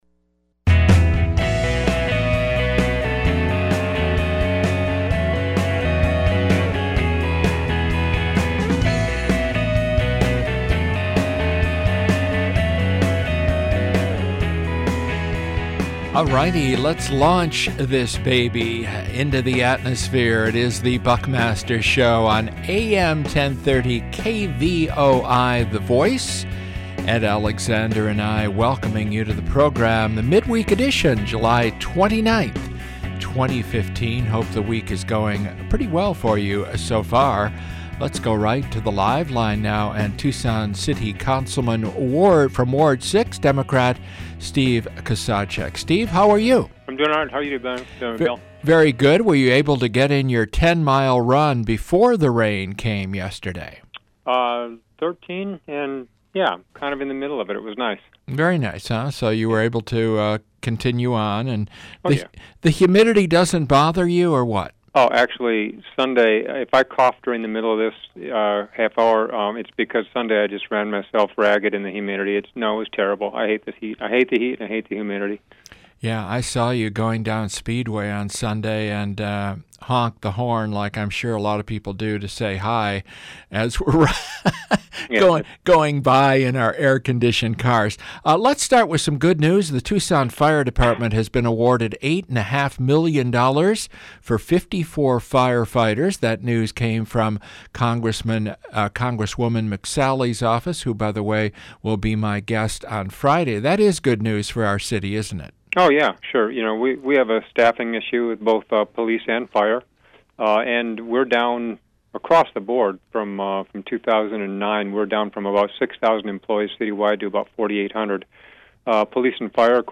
A newsmaker interview with Tucson City Councilman Steve Kozachik (D-Ward 6).